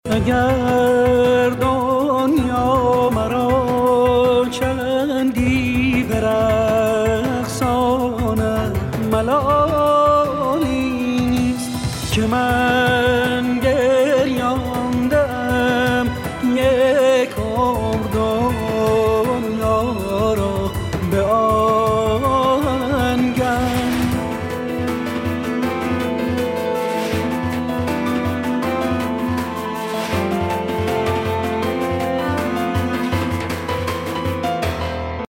رینگتون با کلام حماسی